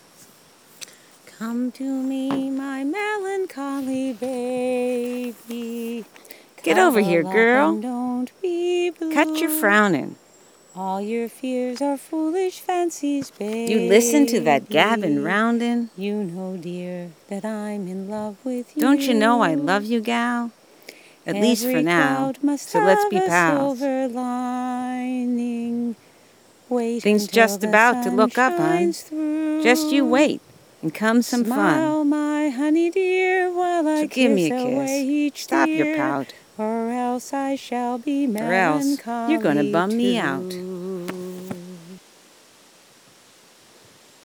(Maybe) (With Musical Accompaniment and Spoken Word) (Ha!)
If you want a good if sorrowful LAUGH, I  urge you to listen to the above recording of the above poem, half of which is sung by yours truly.  (Yes, I have a sore throat, poor equipment, and all the rest of the usual excuses, all in this case true!)